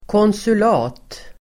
Ladda ner uttalet
Uttal: [kånsul'a:t]